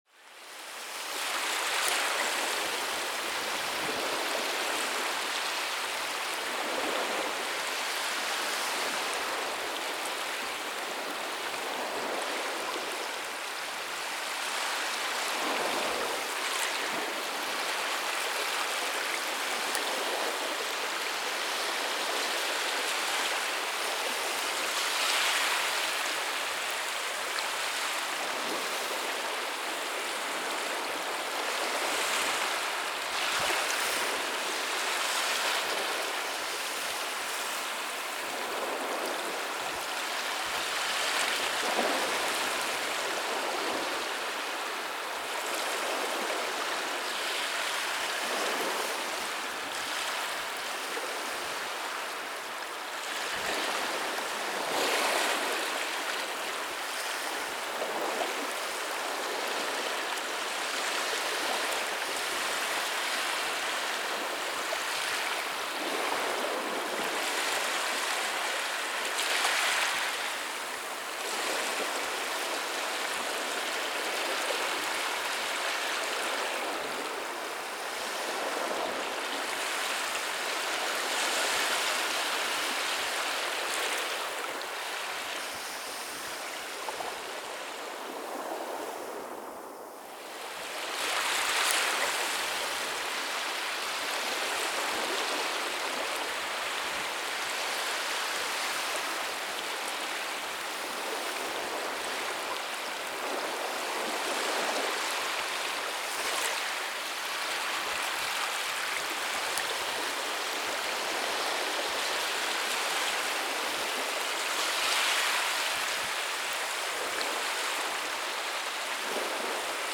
Atmosphere
Each soundscape is designed to create a soothing, inspiring, or immersive atmosphere, depending on your needs.